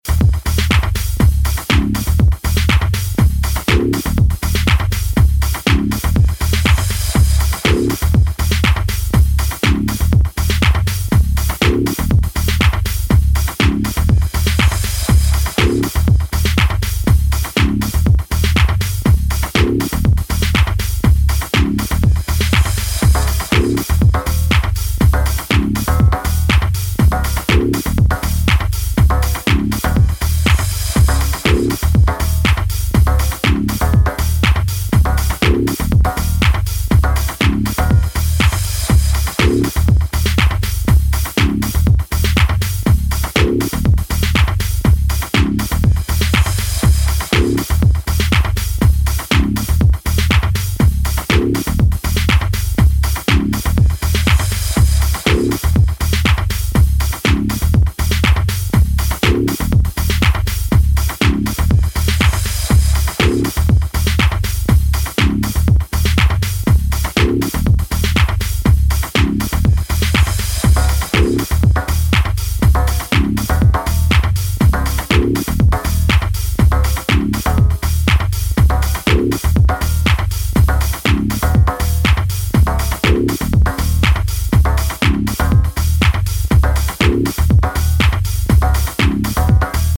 ジャンル(スタイル) DEEP HOUSE / GARAGE HOUSE